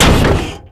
car_heavy_3.wav